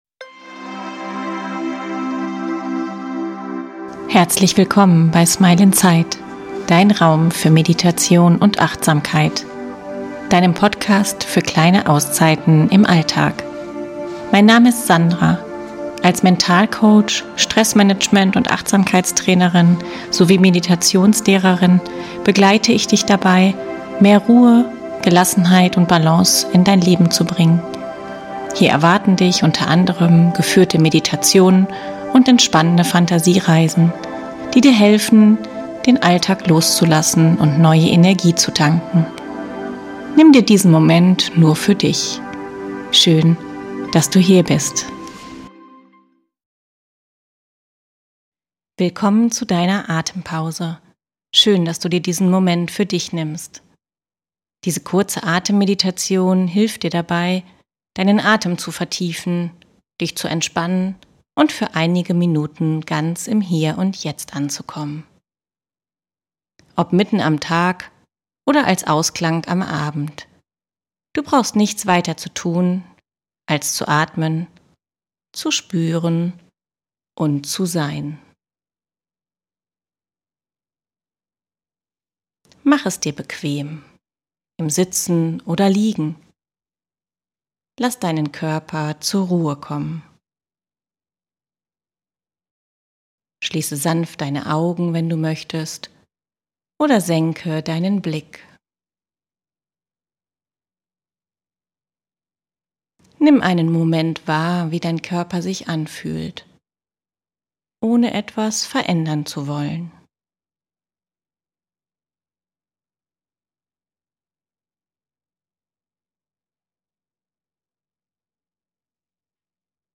In dieser kurzen, geführten Atemmeditation lade ich dich zu einer bewussten Pause ein – mitten im Tag oder als entspannter Ausklang am Abend.